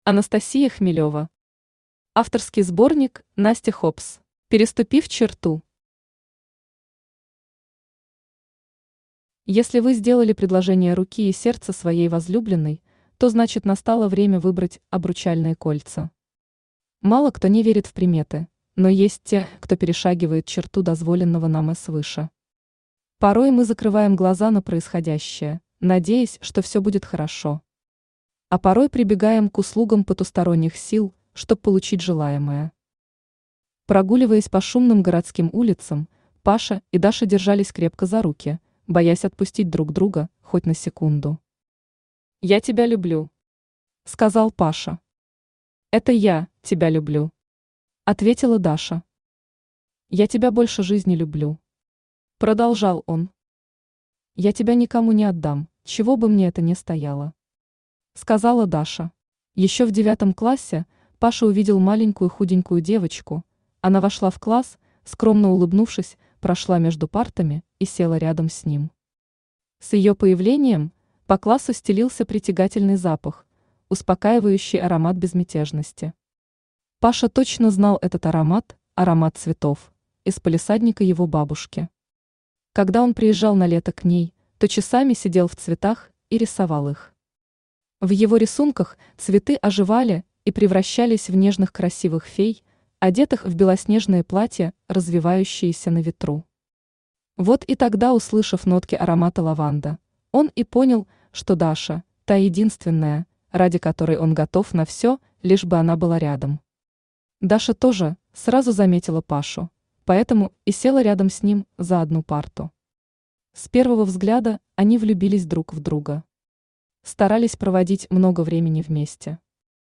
Аудиокнига
Читает аудиокнигу Авточтец ЛитРес.